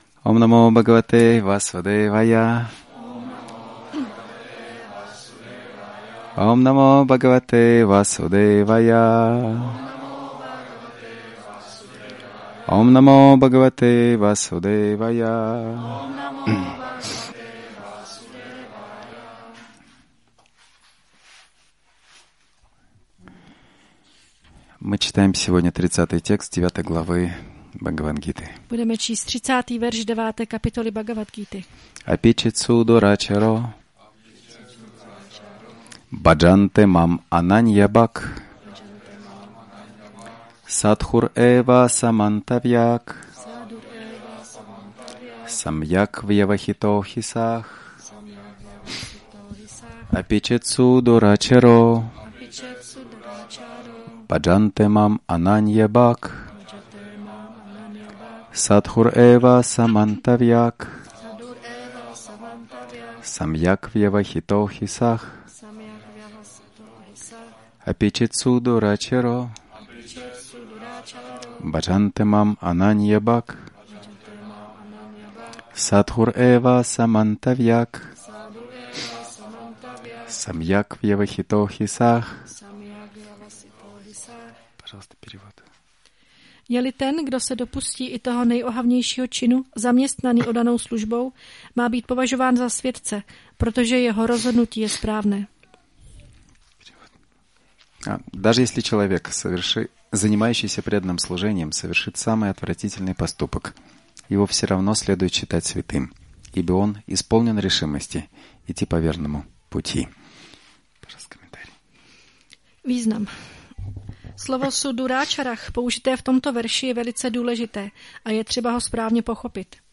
Přednáška BG-9.30